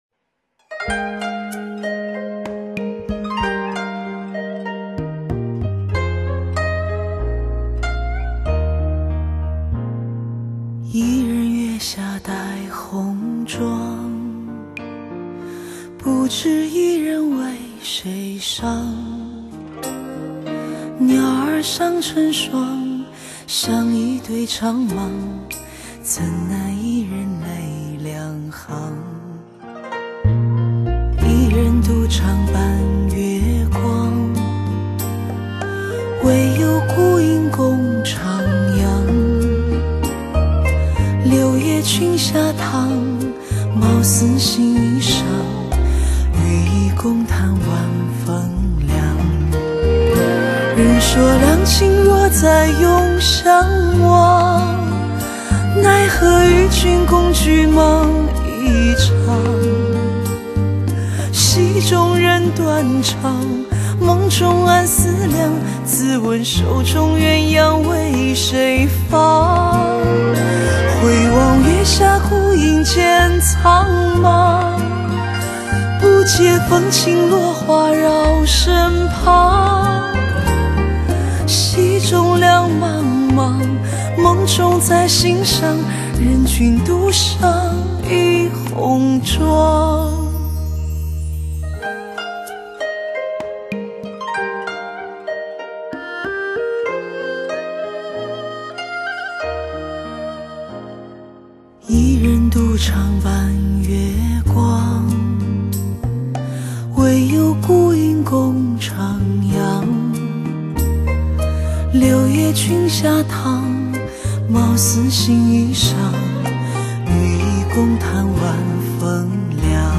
浑然天成丝质沉吟之声  叩开情感最真实感动